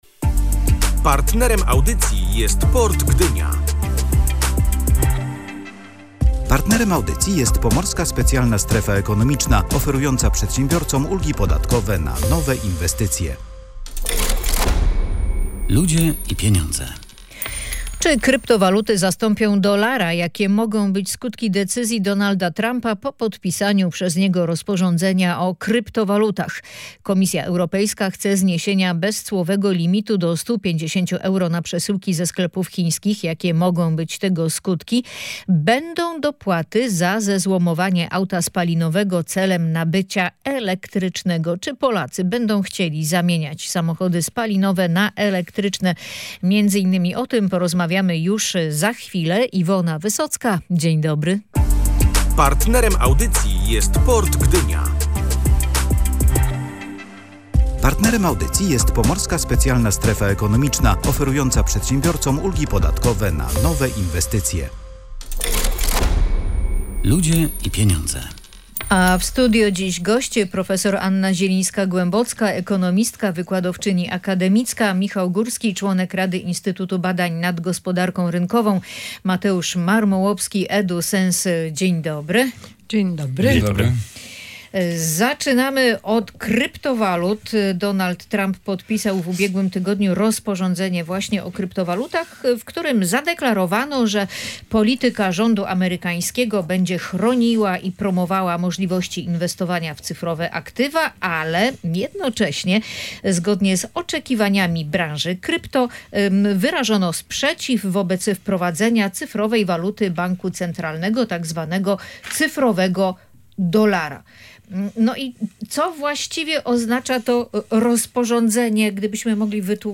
Będą dopłaty za zezłomowanie auta spalinowego celem nabycia elektrycznego. Nowy program dopłat do samochodów elektrycznych był jednym z tematów audycji „Ludzie i Pieniądze”.